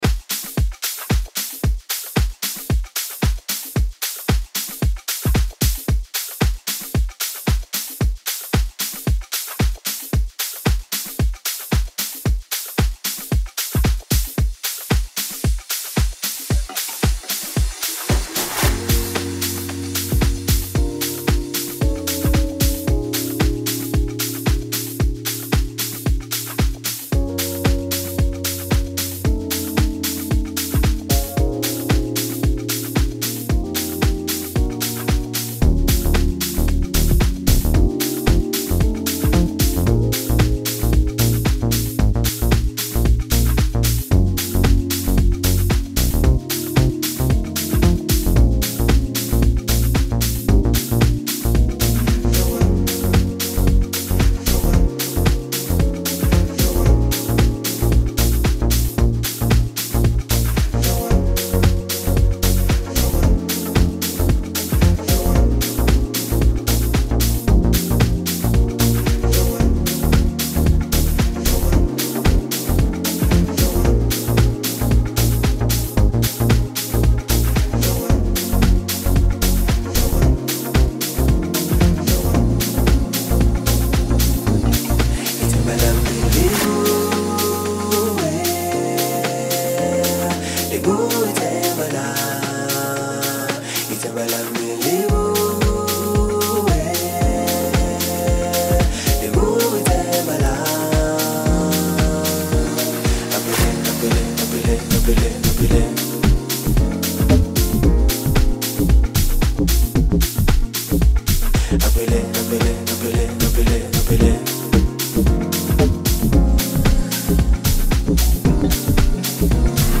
Home » Amapiano